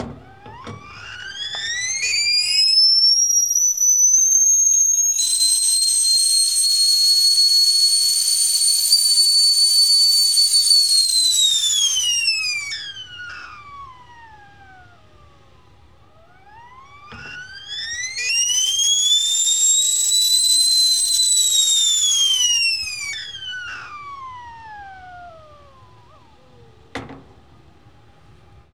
Coffee Pot Whistle Sound
household